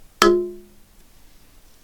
Bonk
Category 😂 Memes
against Bonk crash hit metal thud sound effect free sound royalty free Memes